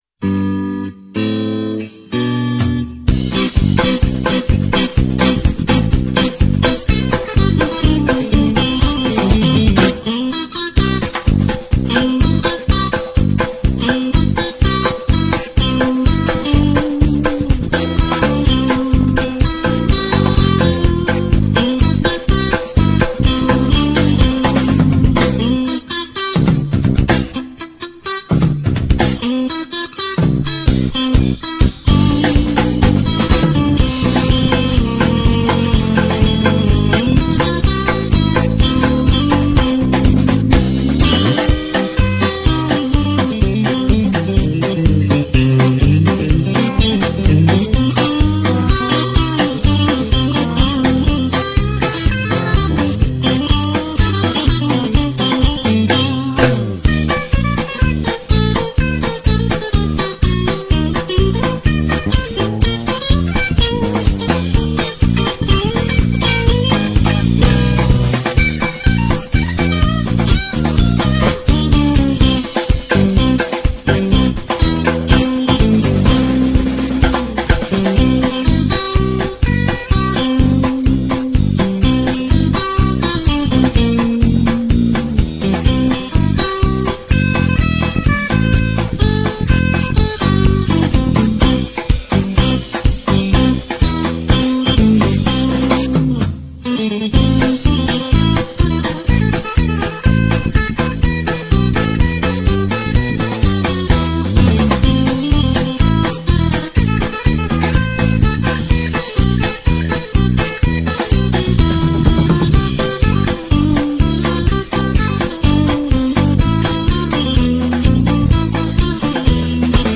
Ambiance jazz cool à jazz rock
guitare
basse
batterie